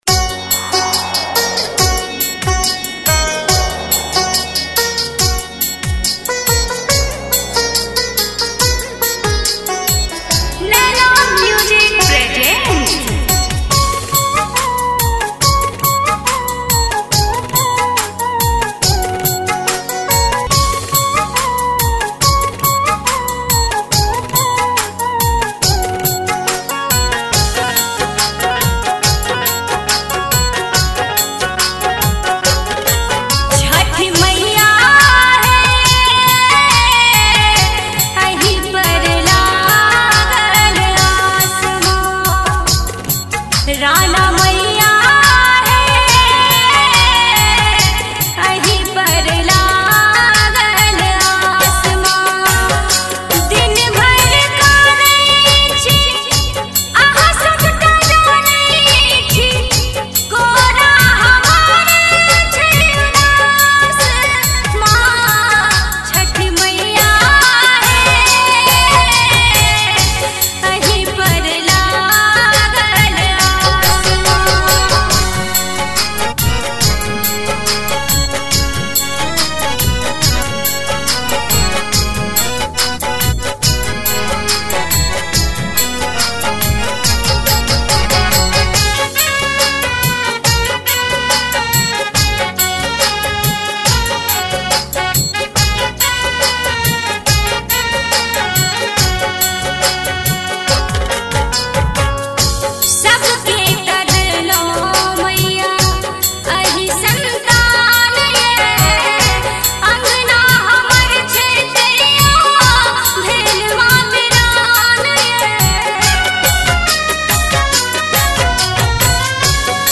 Maithili Chhath Geet